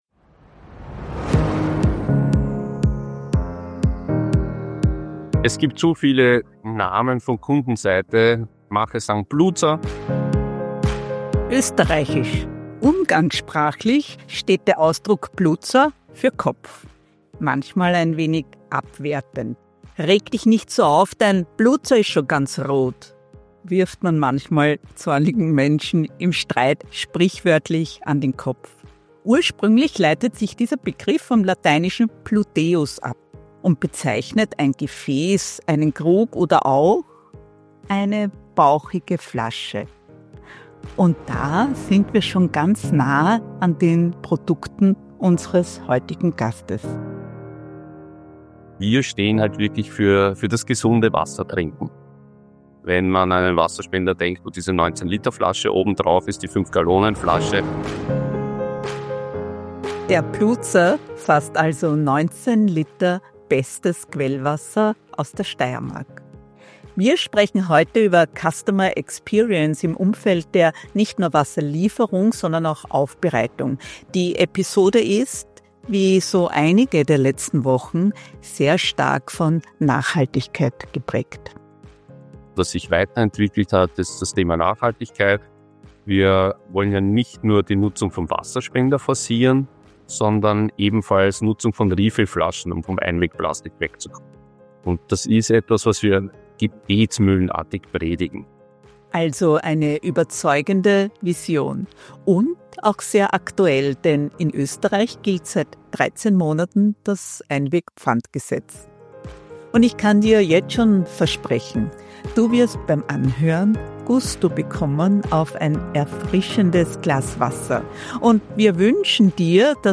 Customer Experience you love with water you love Ein Gespräch